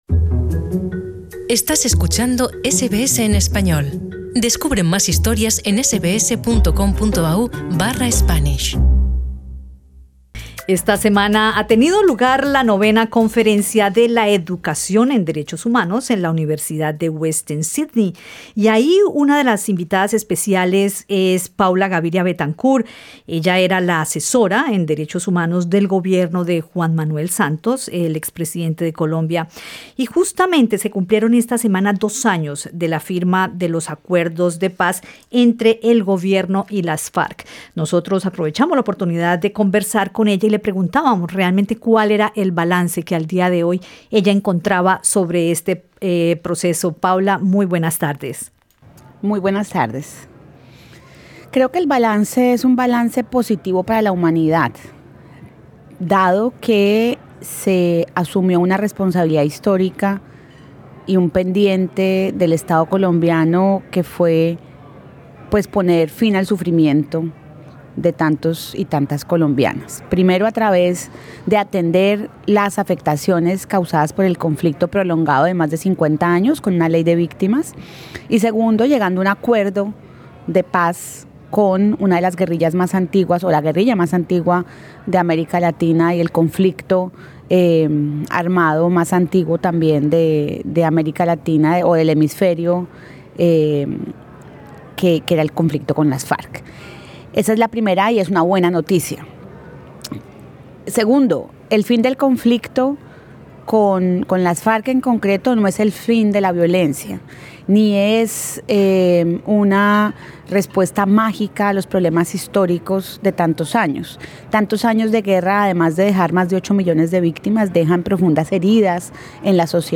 Paula Gaviria Betancur, ex Consejera presidencial de Derechos Humanos hizo un balance sobre los dos años de la firma de los acuerdos de Paz entre la ex guerrilla de las FARC y el gobierno de Colombia en conversación con SBS Spanish.